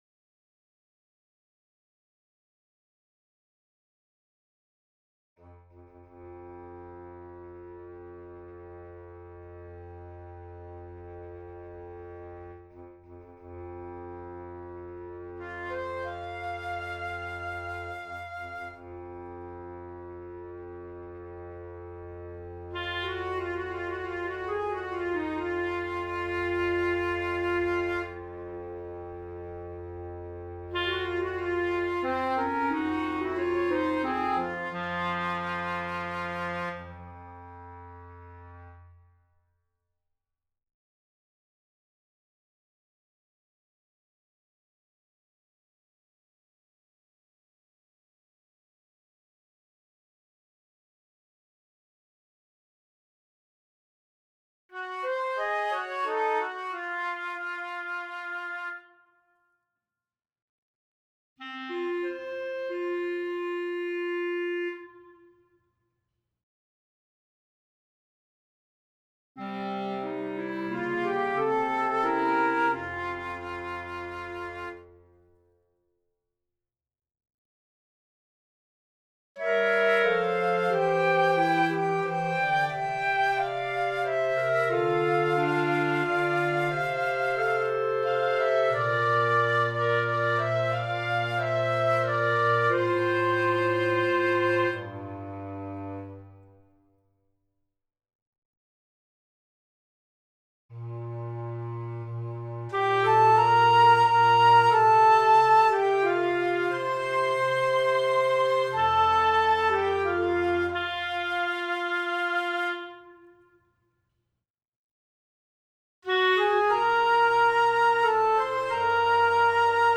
Old Rugged Cross Portrait Woodwinds Stem